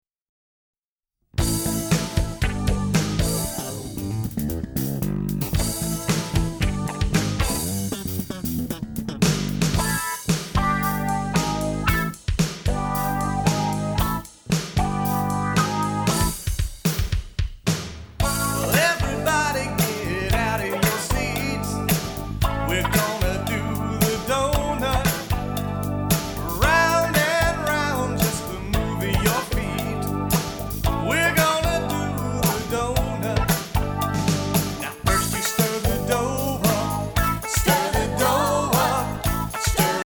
exciting and upbeat Rock, Pop and Funk